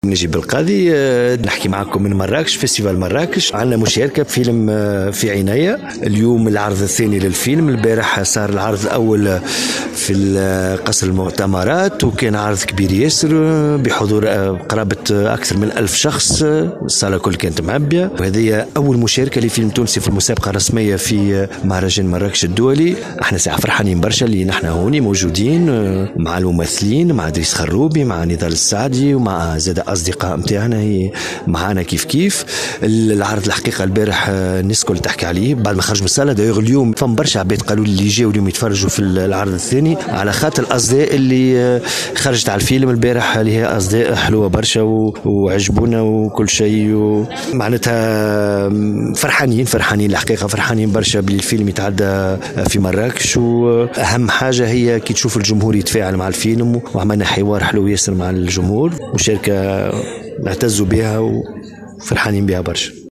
المهرجان الدولي للفيلم بمراكش : "في عينيا" يحصد إعجاب الجمهور والنقاد (تصريحات)